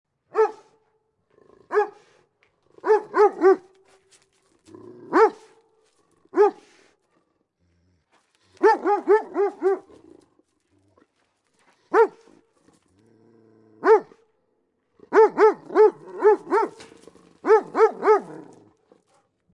Barking Dog 2 Sound Effect Download: Instant Soundboard Button
Dog Barking Sound1,572 views